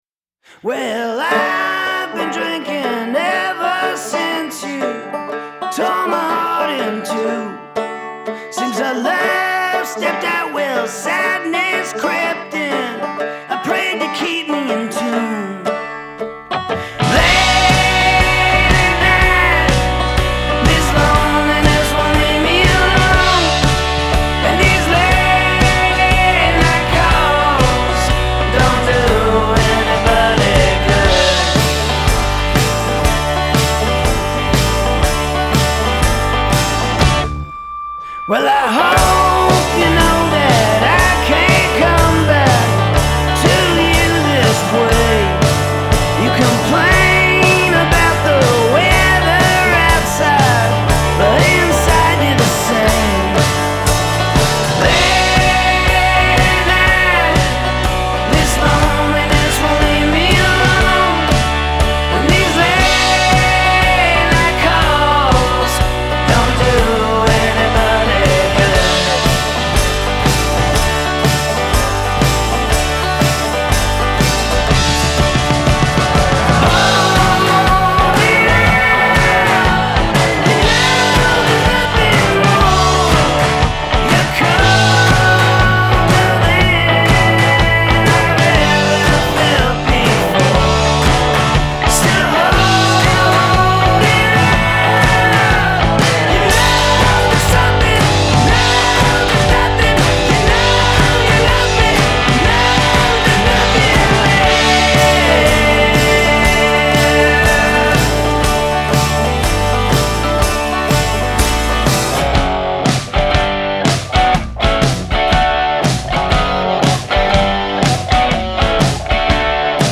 a lively, concise release of roots rock with a mature edge
The lead vocals turn more ragged on the rollicking